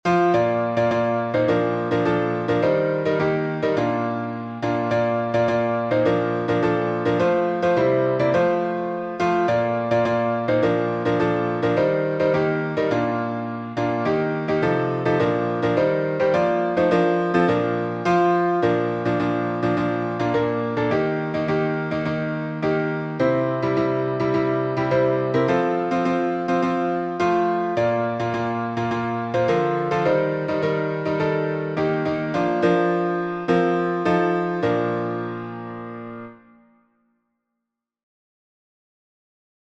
Words by W. C. Martin Tune by C. Austin Miles Key signature: B flat major (2 flats) Time signature: